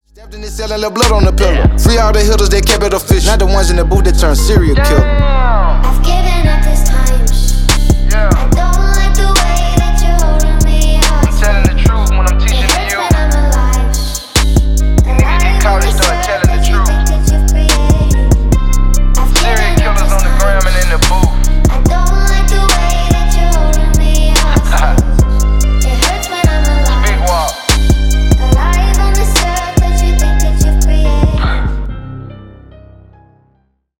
Поп Музыка # Рэп и Хип Хоп